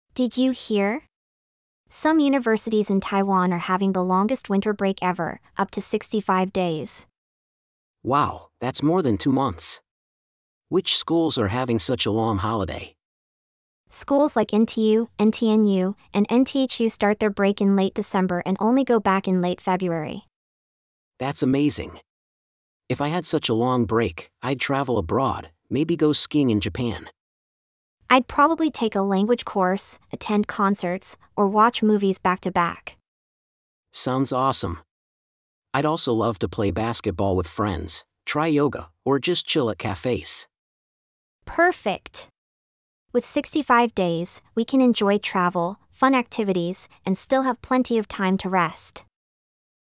英文對話練習